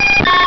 pokeemmo / sound / direct_sound_samples / cries / butterfree.wav